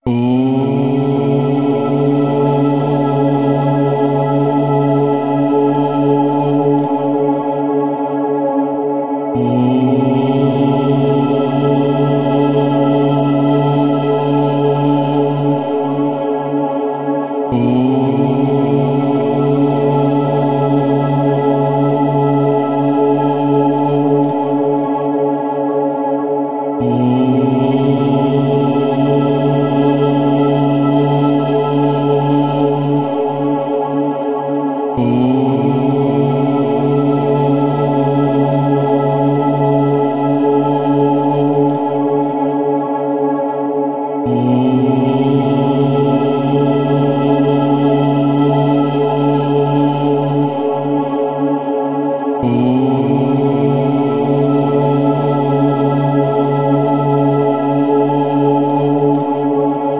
Om Chanting | Har Har Mahadev
Namaste 🙏 Thank you for Watching 🙏 Please Like, Comment, Share, and Subscribe 🙏🙏🙏 🕉 The Power of Om Chanting & Har Har Mahadev Experience the ancient vibration of Om Chanting, one of the most sacred and transformative sounds in the universe.
432Hz Om Vibration